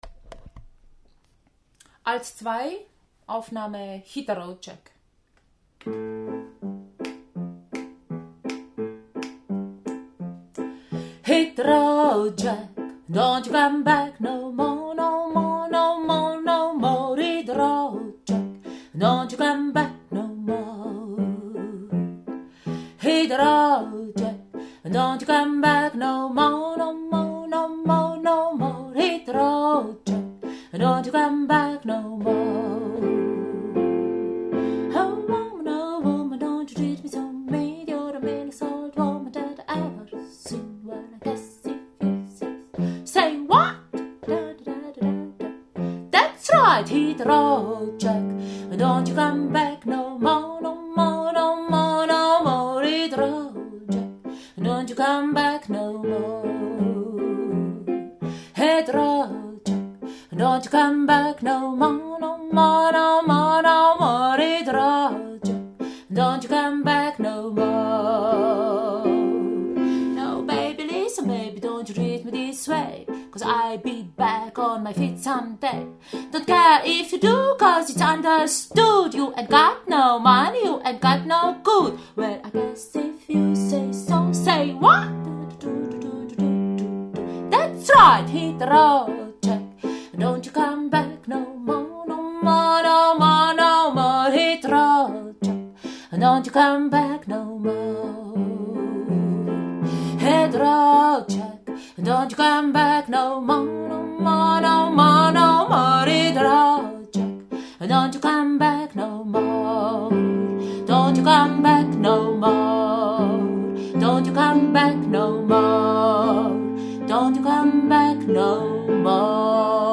Hit the road Jack – Alto2